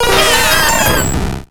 Cri de Mew dans Pokémon X et Y.